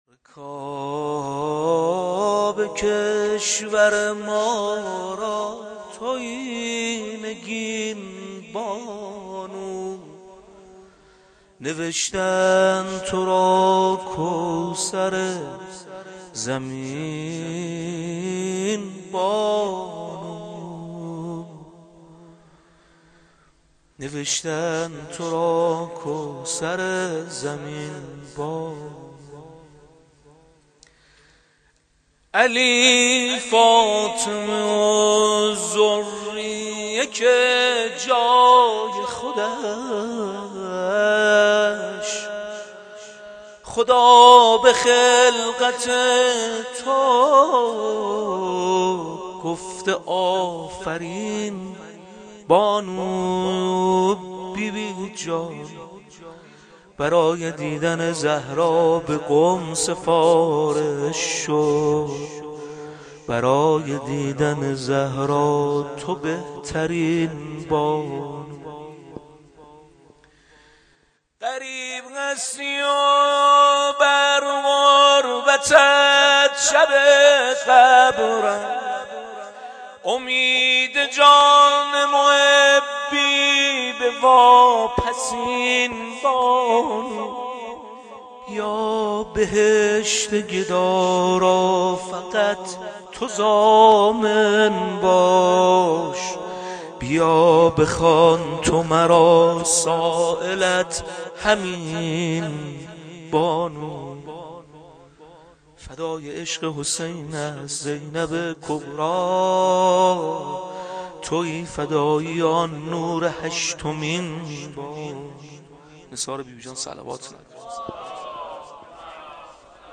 مدح حضرت معصومه